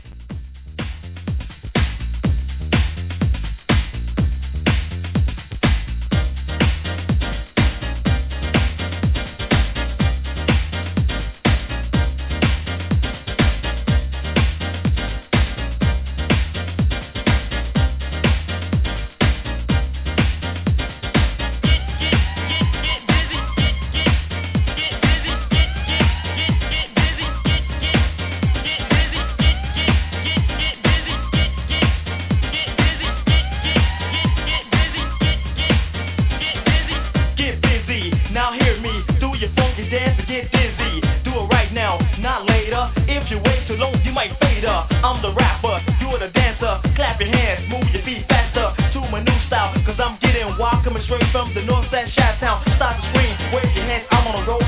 HIP HOUSE!!